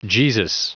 Prononciation du mot jesus en anglais (fichier audio)
Prononciation du mot : jesus